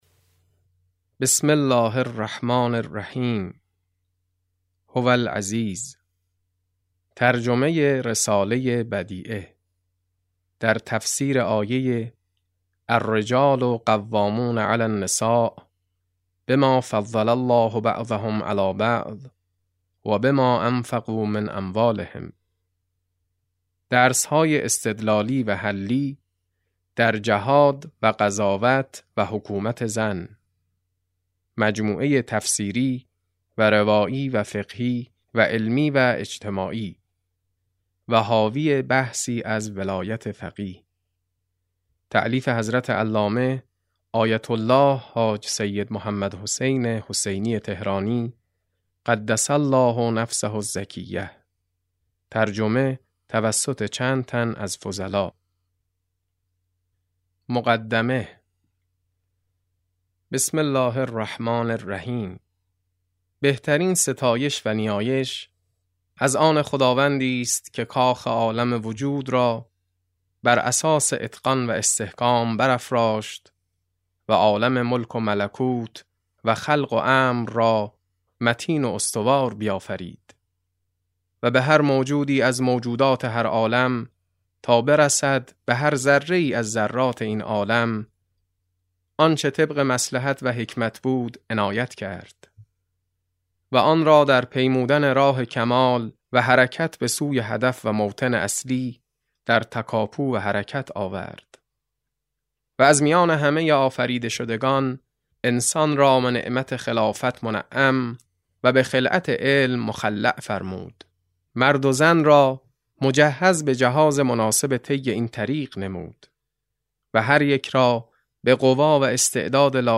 رساله بدیعه - مقدمه - کتاب صوتی - کتاب صوتی رساله بدیعه - بخش2 - علامه طهرانی | مکتب وحی